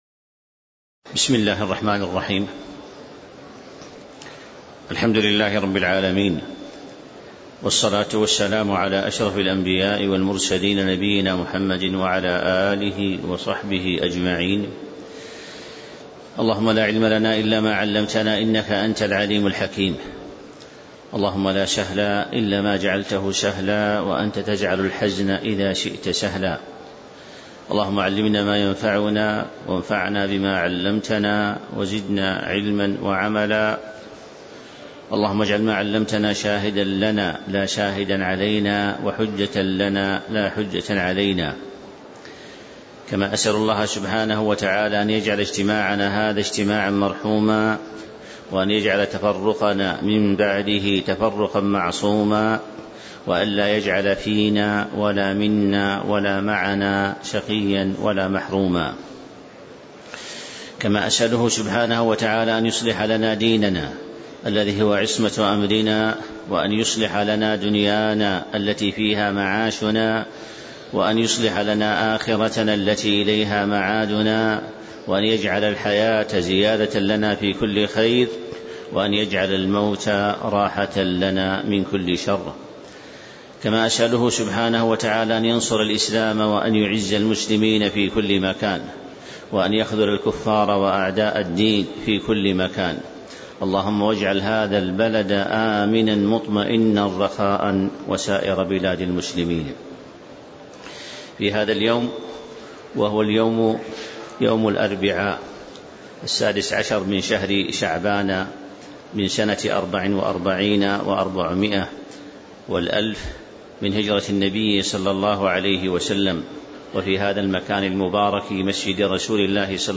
تاريخ النشر ١٦ شعبان ١٤٤٤ هـ المكان: المسجد النبوي الشيخ